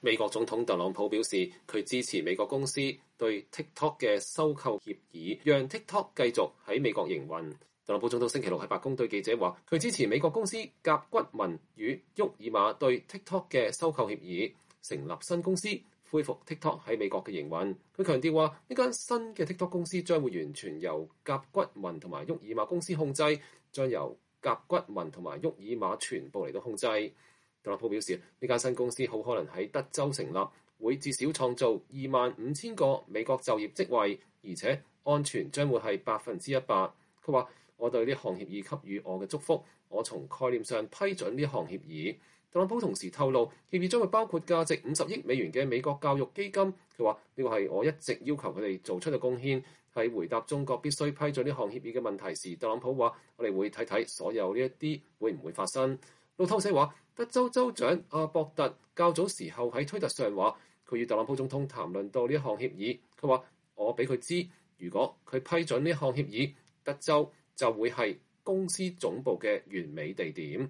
美國總統特朗普9月18日在白宮對記者發表講話。